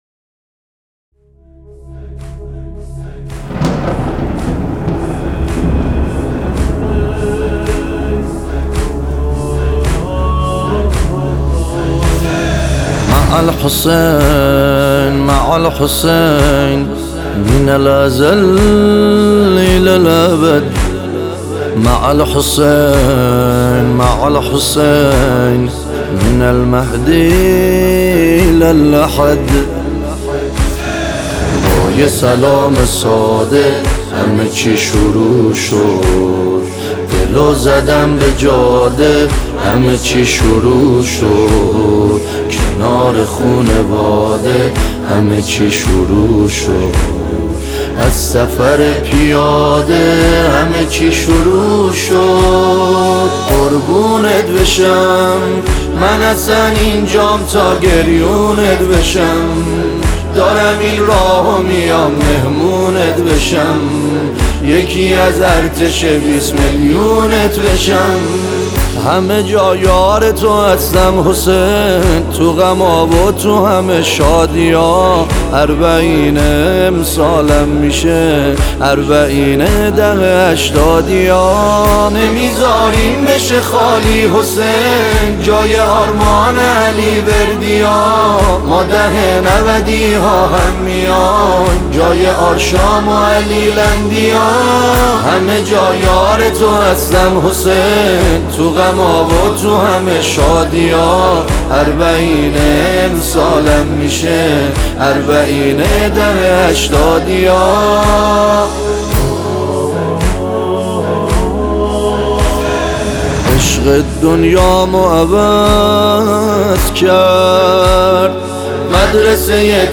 گروه سرود یزد